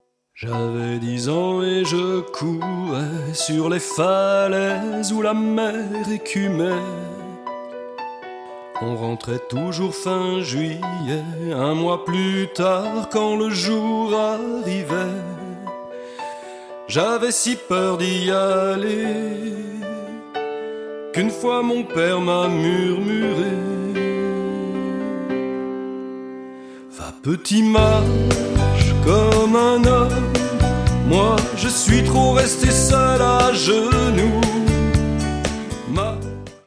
Batterie
Basse
Piano et orgue
Guitares